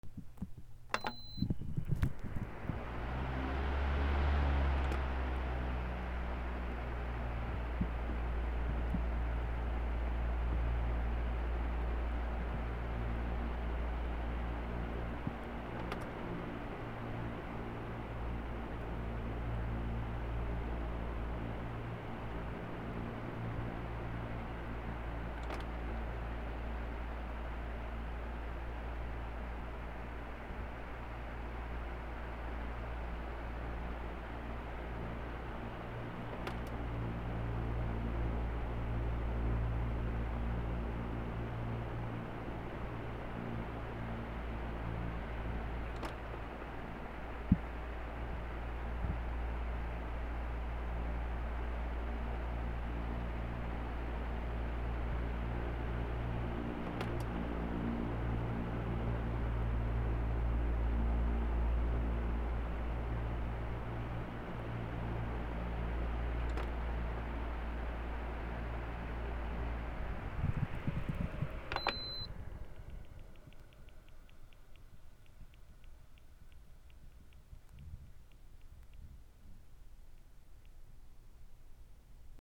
扇風機(首振り 弱)
/ M｜他分類 / L10 ｜電化製品・機械